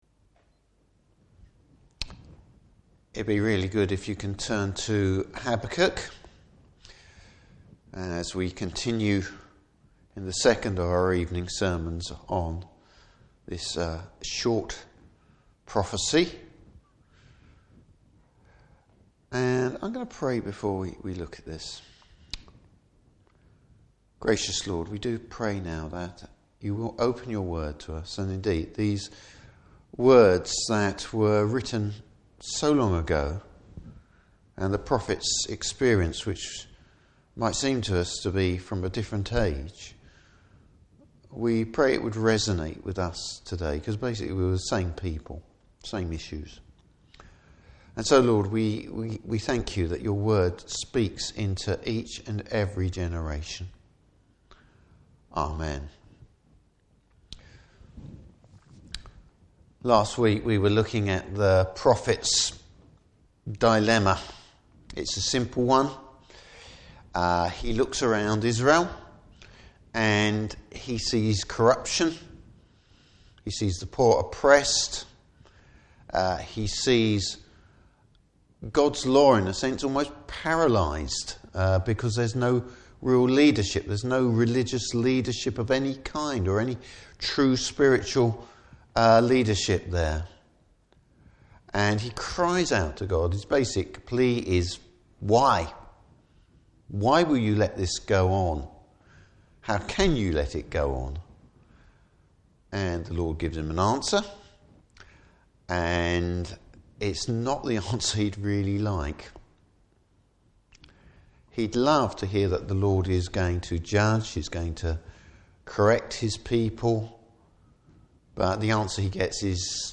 Service Type: Evening Service An insight into the Lord’s sovereignty over the nations.